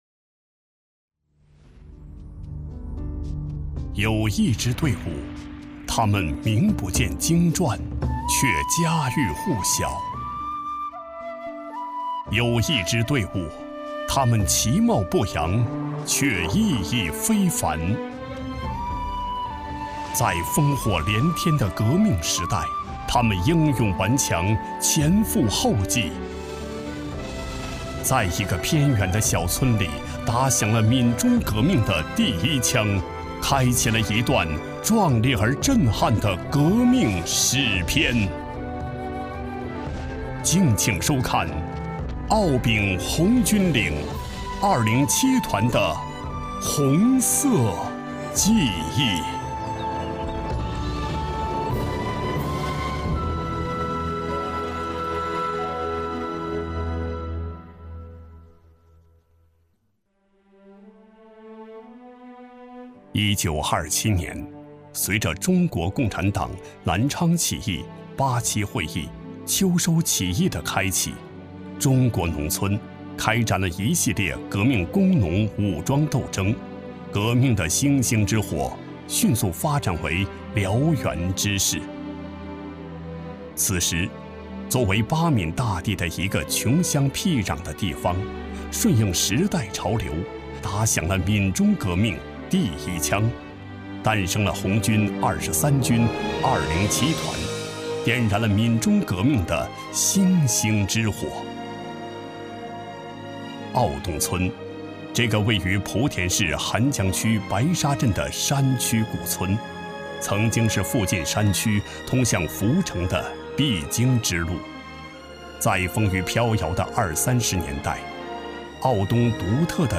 职业配音员，拥有国家普通话测试一级甲等证书，声音大气浑厚，擅长专题类，宣传类稿件。
特点：大气浑厚 稳重磁性 激情力度 成熟厚重
类别:专题片
风格:浑厚配音